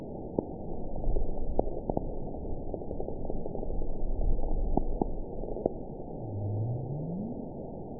event 920780 date 04/08/24 time 20:03:27 GMT (1 year, 2 months ago) score 9.38 location TSS-AB05 detected by nrw target species NRW annotations +NRW Spectrogram: Frequency (kHz) vs. Time (s) audio not available .wav